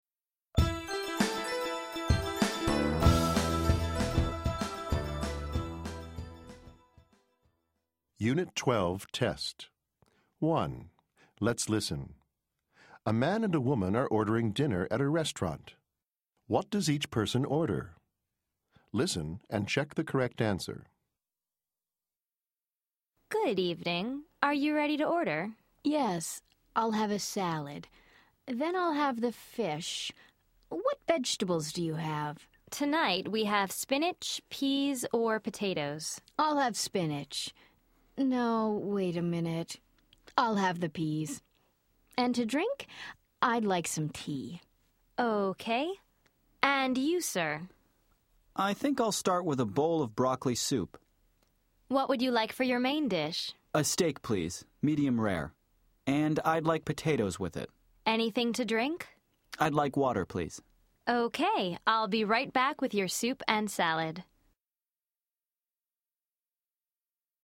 A man and woman are ordering dinner at restaurant. What does each person order?